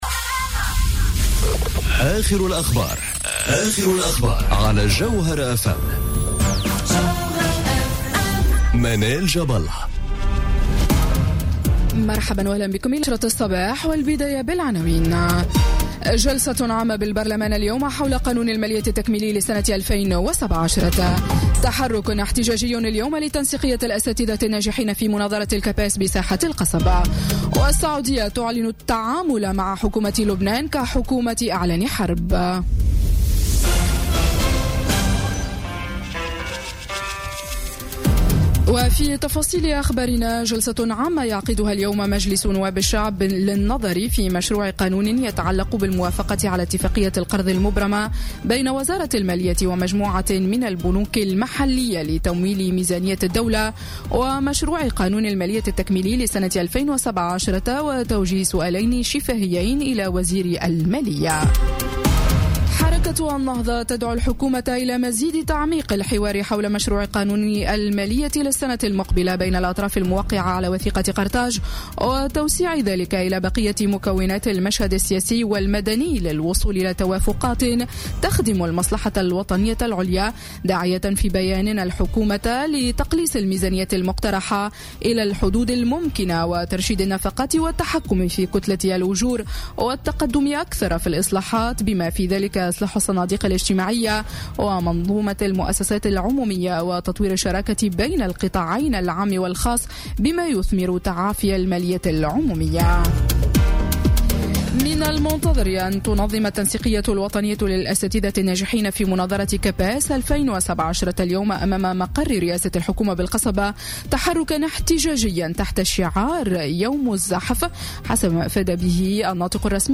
نشرة أخبار السابعة صباحا ليوم الثلاثاء 07 نوفمبر 2017